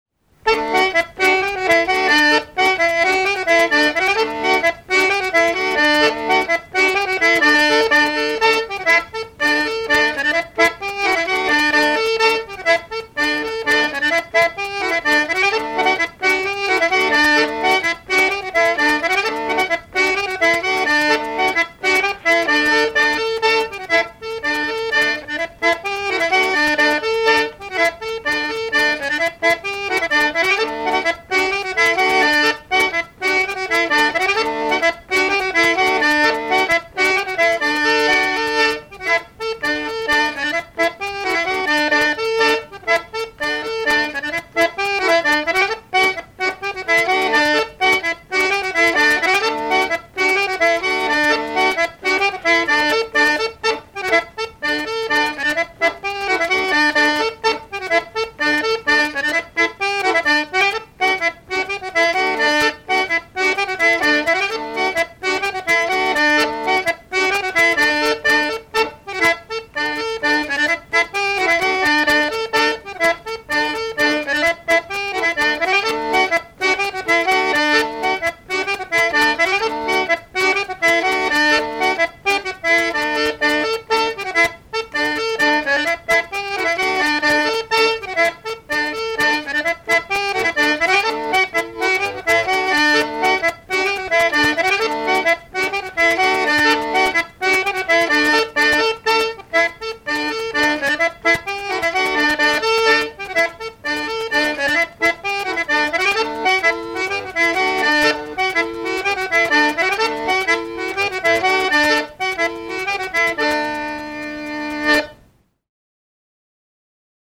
danse : angoise, maristingo
Répertoire d'airs à danser
Pièce musicale inédite